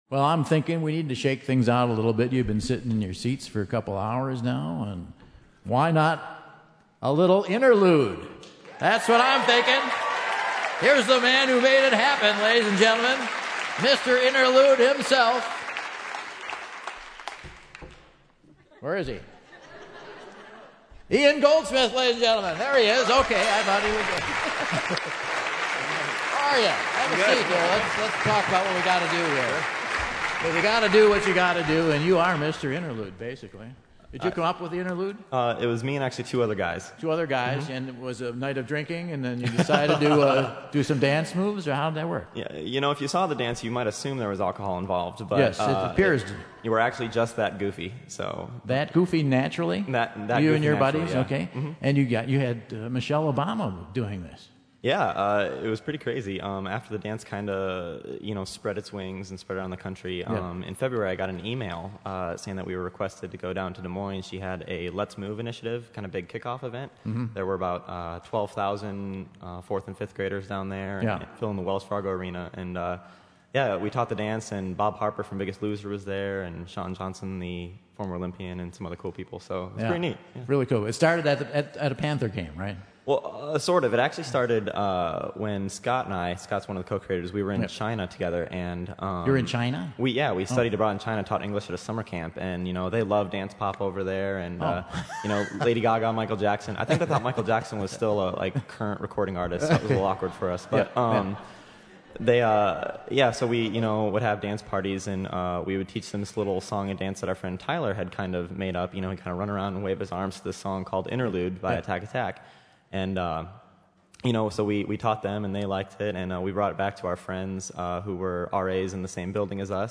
gets Michael and the crowd on their feet to teach them the "Interlude" dance!